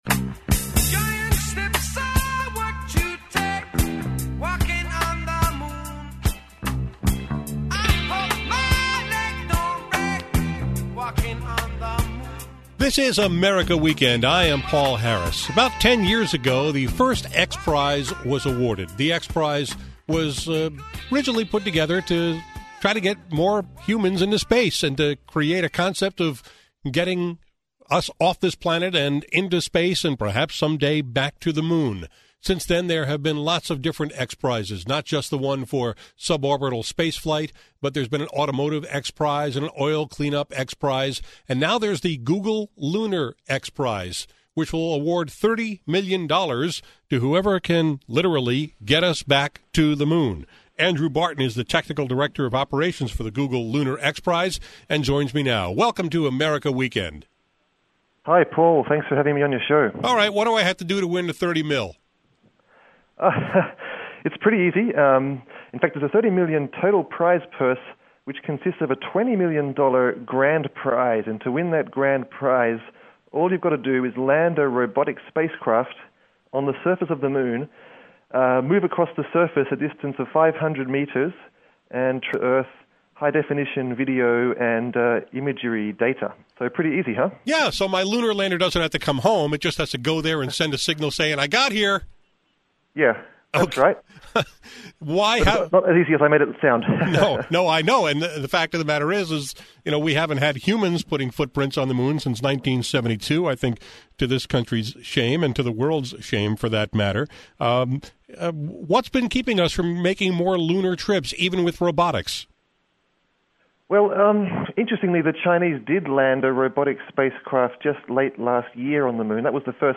Apr 7, 2014 | podcasts, Science